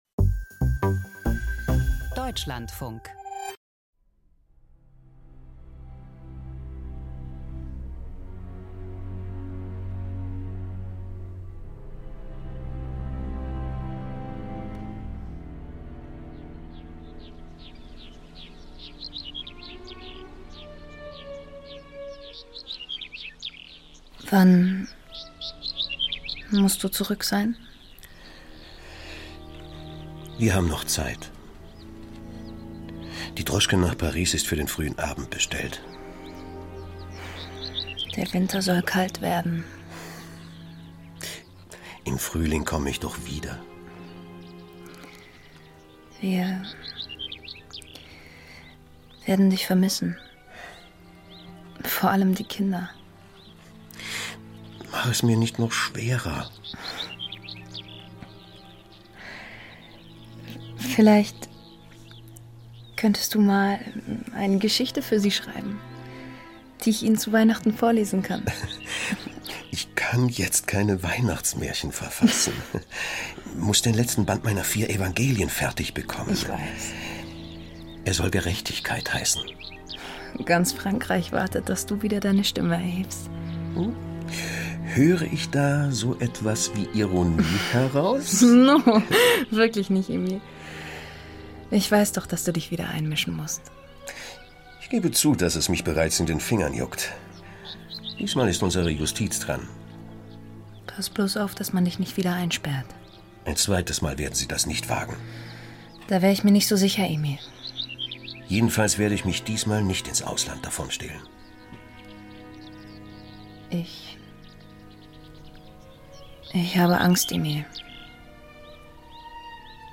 Klangkunst